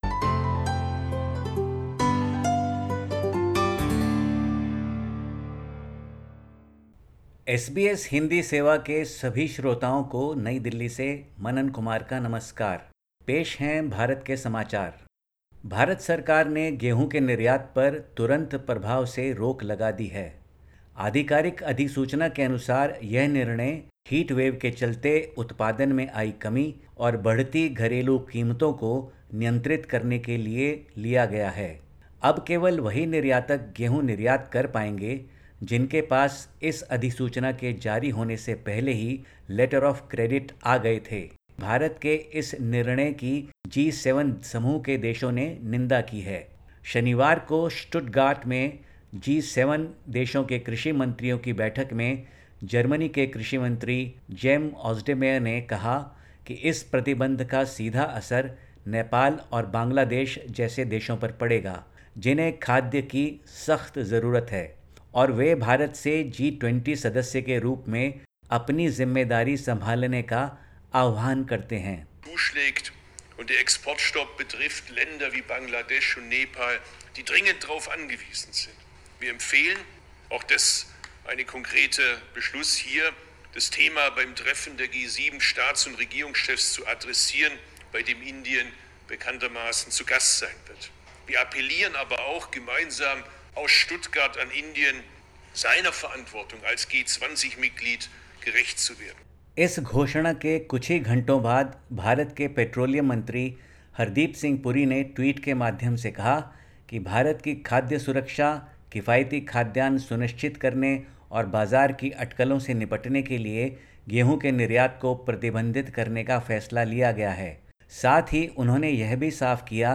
Listen to the latest SBS Hindi report from India. 16/05/2022